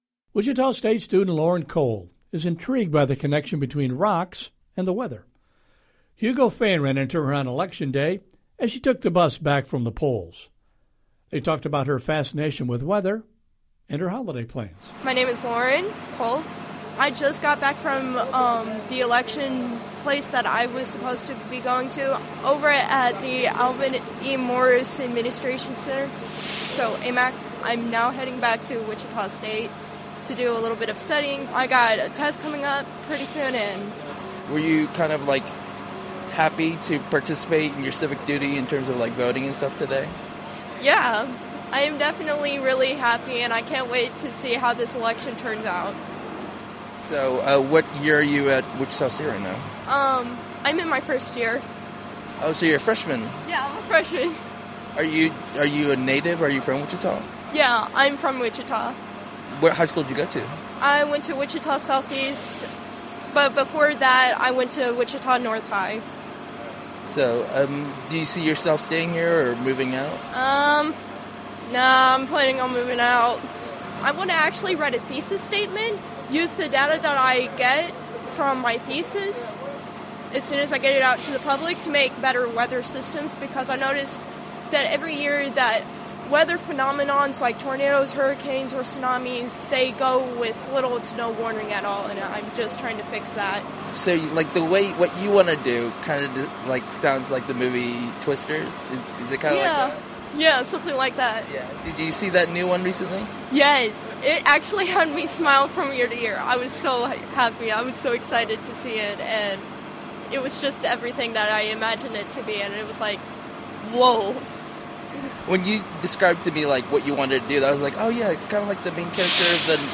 We ride the bus with a Wichita State student who is passionate about the weather on this month's "En Route."